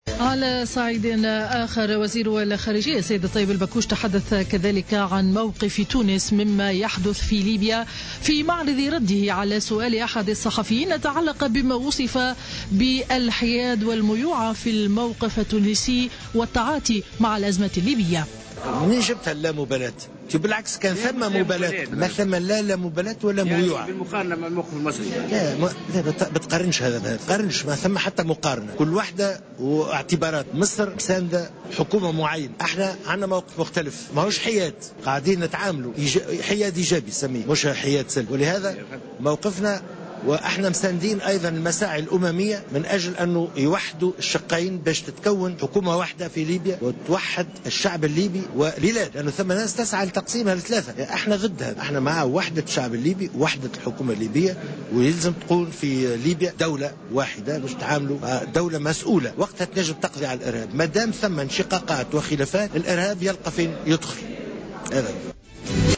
تحدث اليوم الاحد وزير الخارجية الطيب البكوش عن موقف تونس مما يجري في ليبيا ردا على سؤال من أحد الصحفيين وصف الموقف التونسي بالميوعة والحياد والسلبية.